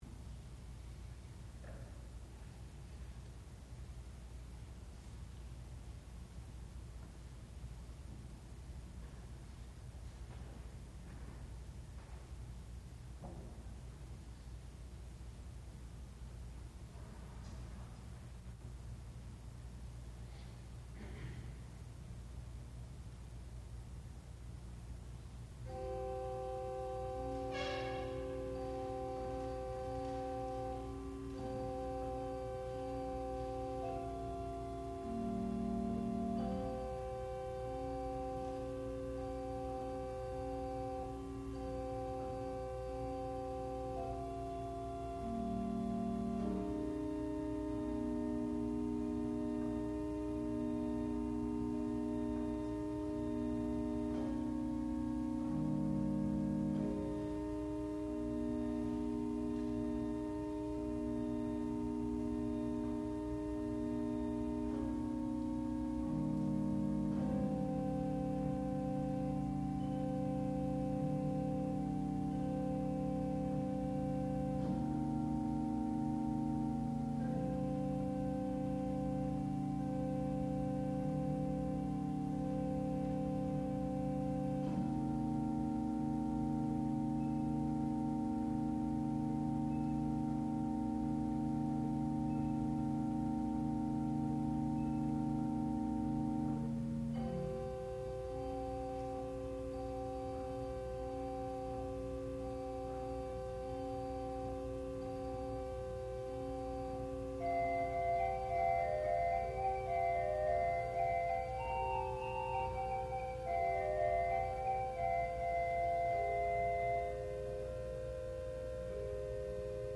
sound recording-musical
classical music
Graduate Recital
organ